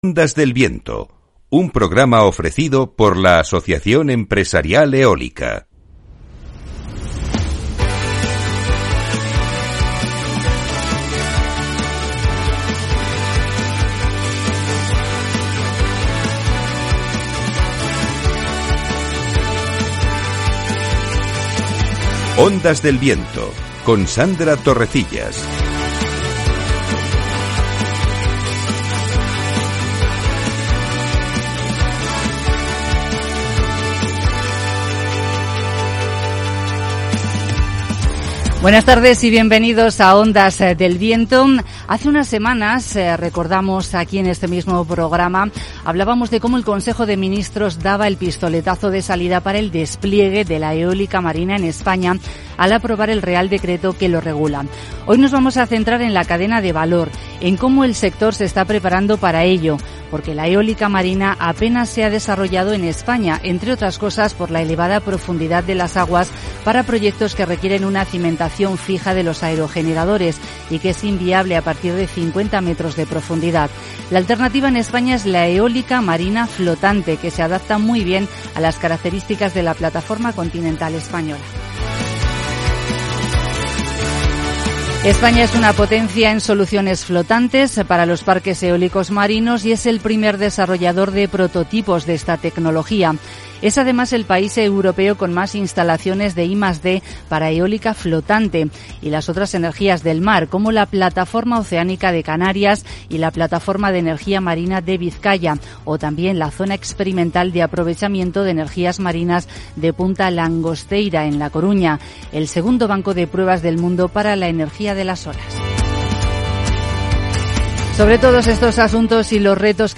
Nuestros expertos invitados los han ido resumiendo en este nuevo programa de Ondas del Viento: